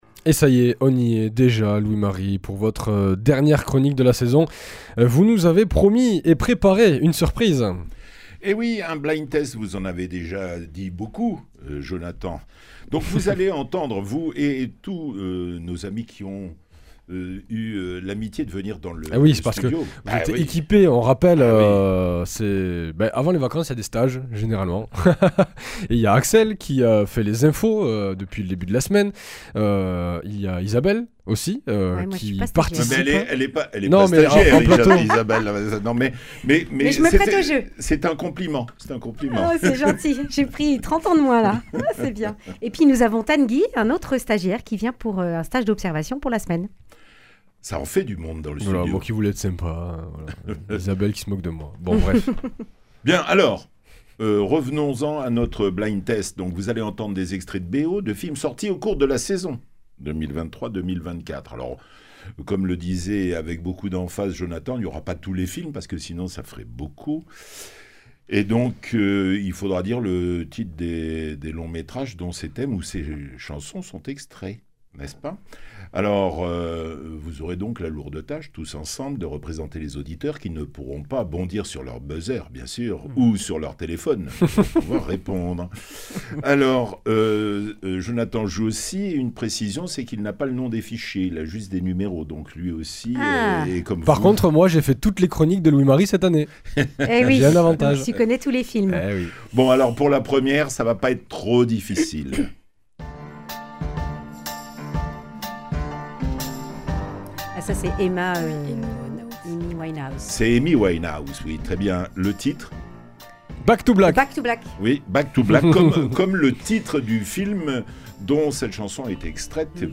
Pour cette dernière chronique avant la pause estivale, un blind test, ce jeu qui consiste à reconnaître le plus rapidement possible des extraits de musique. Pour l’occasion ce sont des passages de bandes originales de films sortis au cours de la saison radio 2023/2024 qu’il faudra trouver.